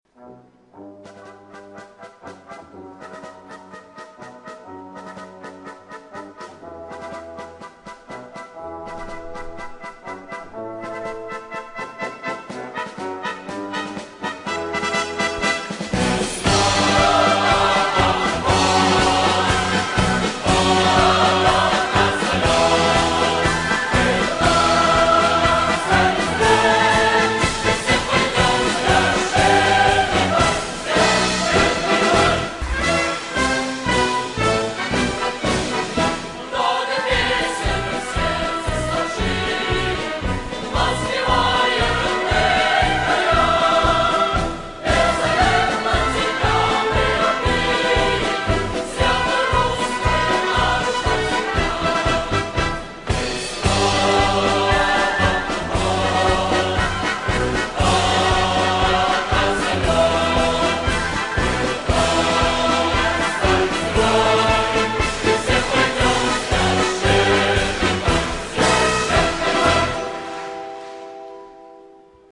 Фрагмент песни, из концертной исполнительный.